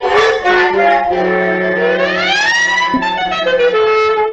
Old Timey Fail
fail_mRkebHz.mp3